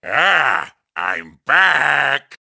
One of Wario's voice clips in Mario Kart Wii